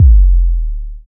SI2 HEARTB00.wav